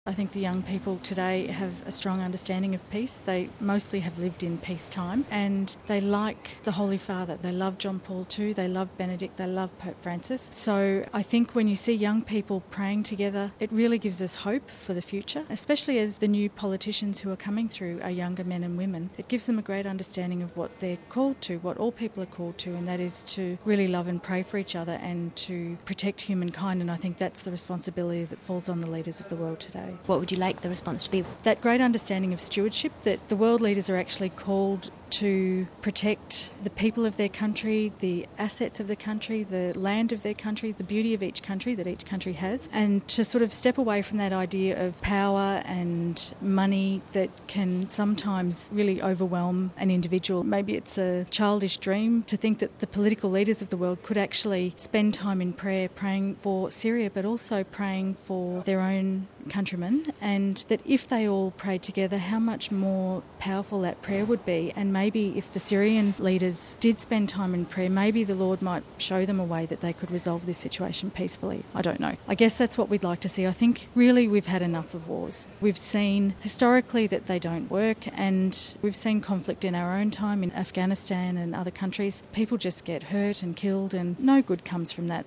(Vatican Radio) Over a hundred thousand people joined Pope Francis for a prayer vigil in St. Peter’s Square last night, as part of an international day of prayer and fasting for peace in Syria and the world.